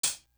Tick Tock Hat.wav